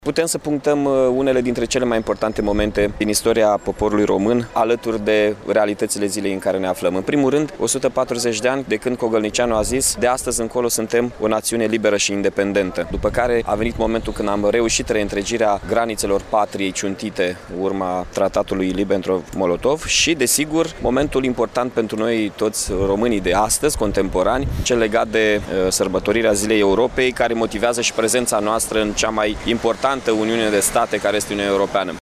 La Iaşi, în zona Monumentului Independenţei, în prezenţa oficialităţilor locale şi judeţene, au avut loc ceremonii militare şi religioase.
La rândul său, primarul Iaşului, Mihai Chirica, a spus că intrarea României în Uniunea Europeană  implică pentru noi românii atât drepturi cât şi obligaţii, pe care trebuiesă ni le asumăm şi să le respectăm.